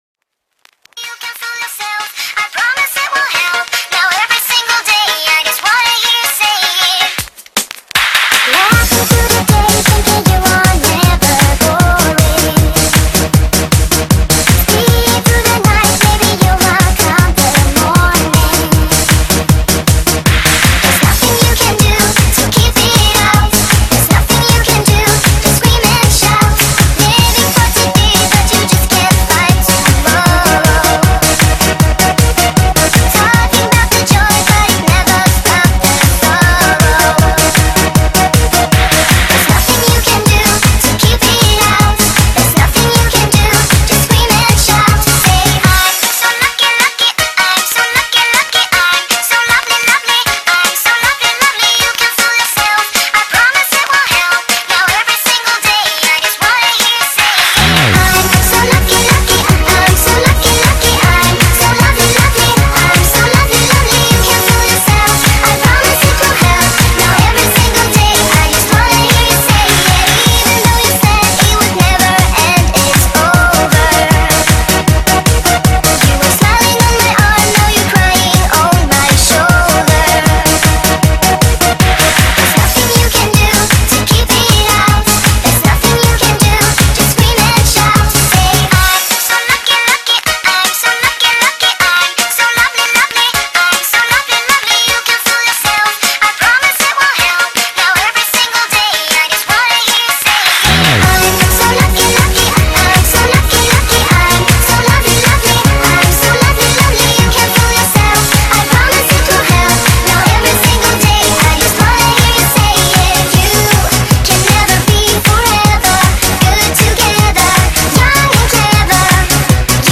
Ремиксы